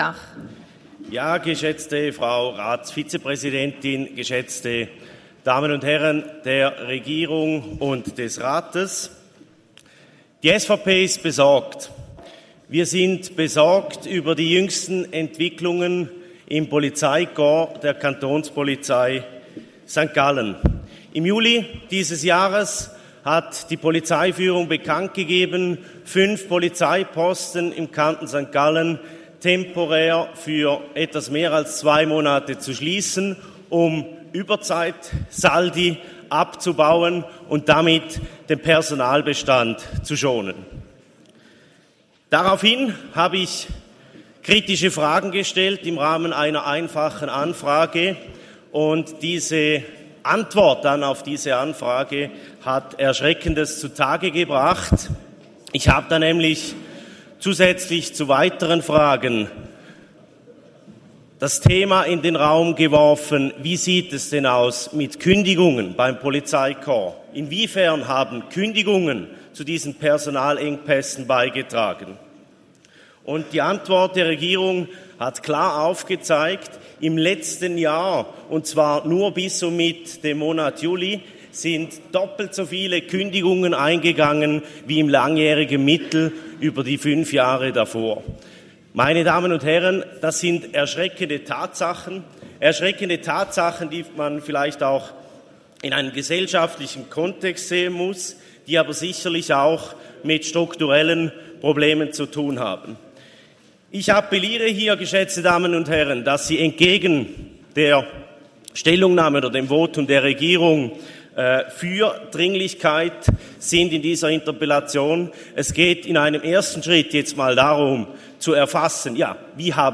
Session des Kantonsrates vom 28. bis 30. November 2022